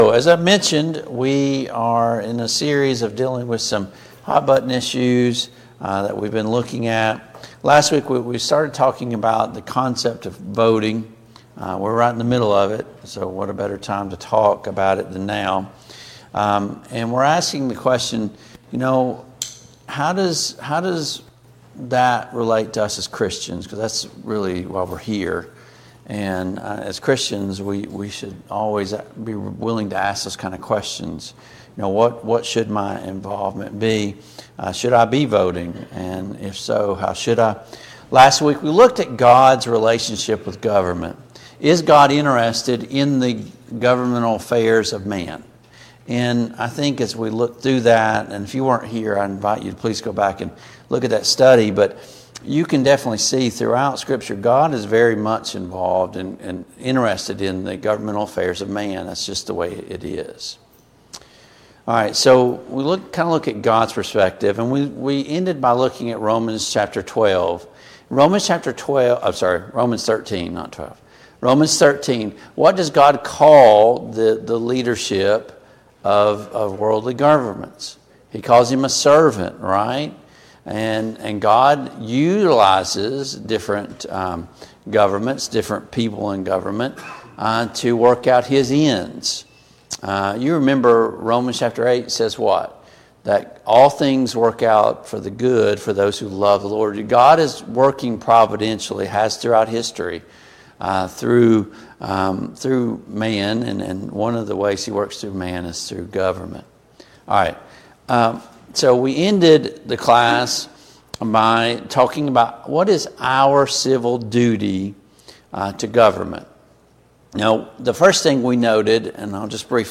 Service Type: Sunday Morning Bible Class